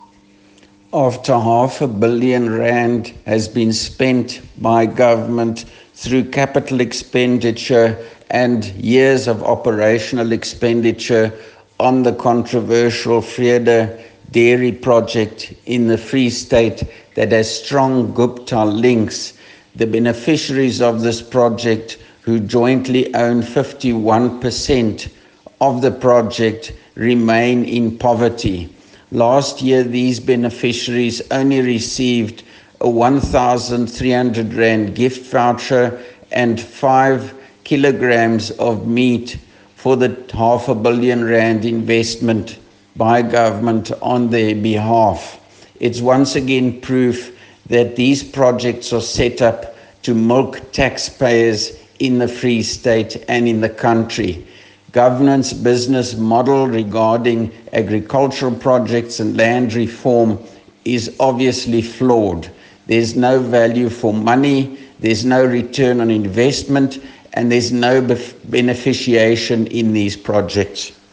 Afrikaans soundbites by Roy Jankielsohn MPL and